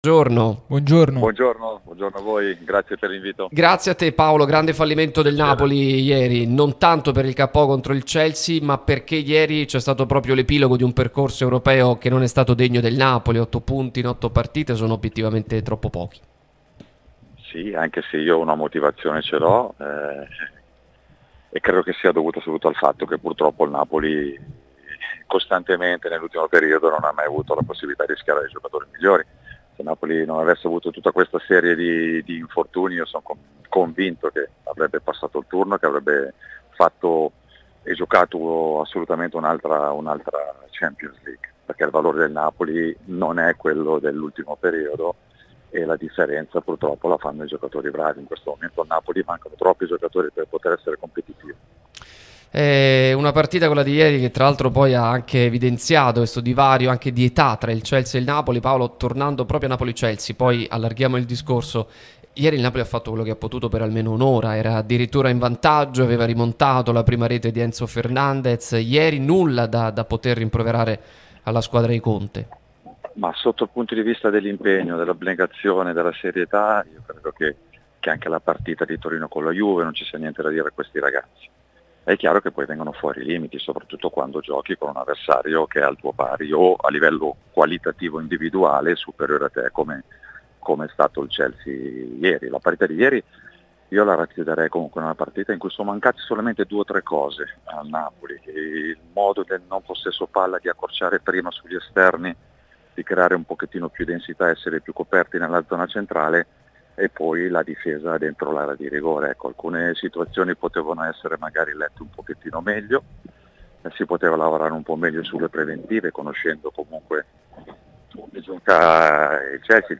Il tecnico, ed ex calciatore Paolo Tramezzani, è intervenuto a Pausa Caffè su Radio Tutto Napoli, prima radio tematica sul Napoli, che puoi ascoltare/vedere qui sul sito, in auto col DAB Campania o sulle app gratuite (scarica qui per Iphone o qui per Android).